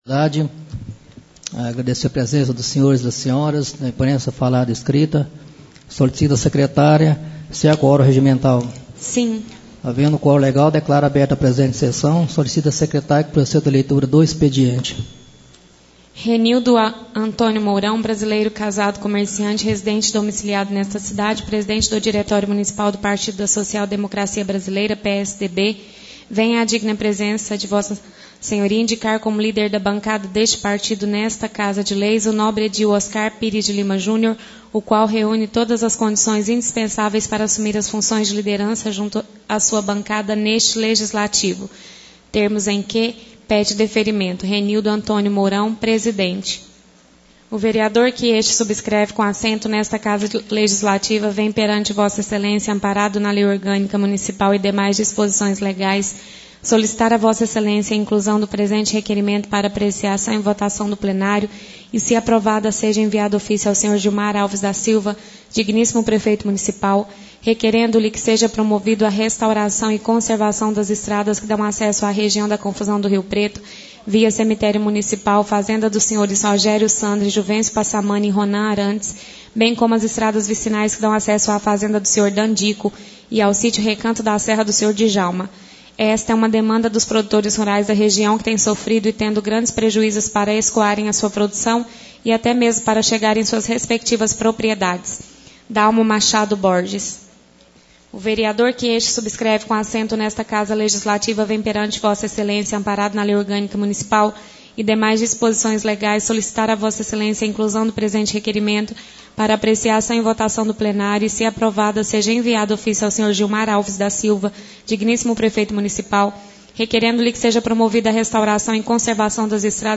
Segunda Sessão Ordinária da Câmara Municipal Fevereiro